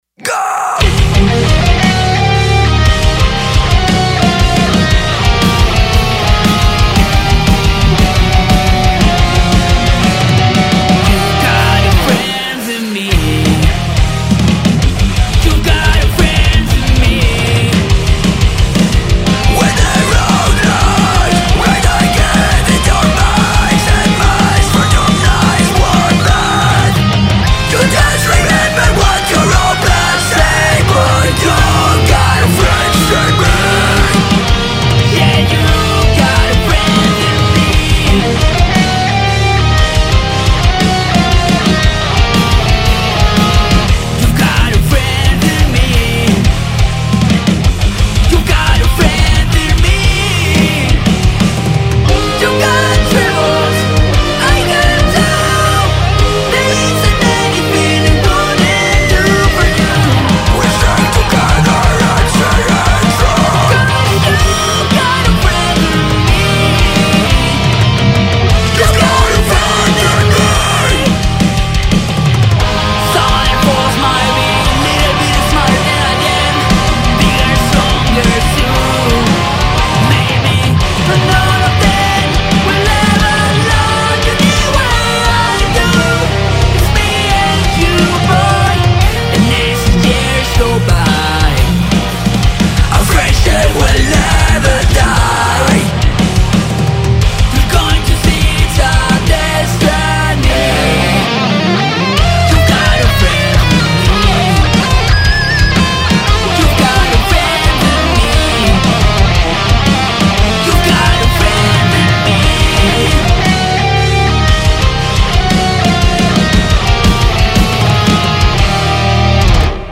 Metal cover